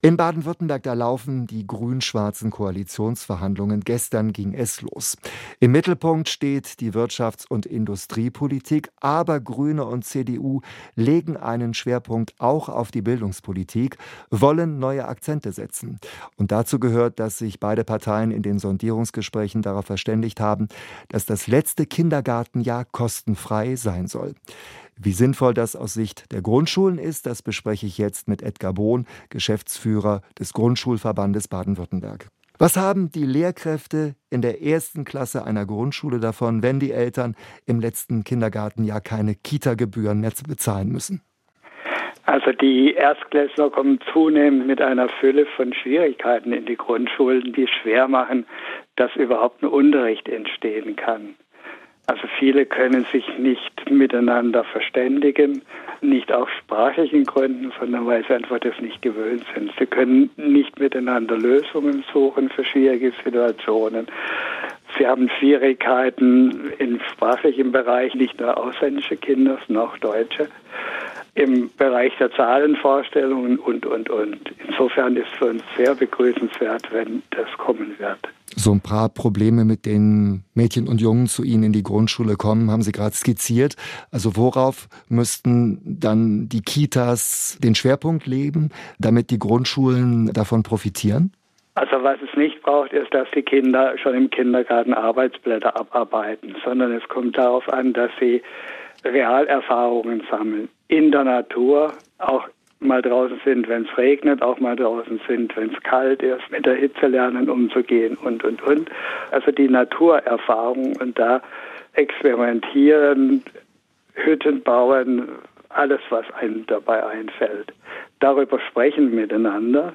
Die besten Interviews aus dem Radioprogramm SWR Aktuell: jederzeit zum Nachhören und als Podcast im Abo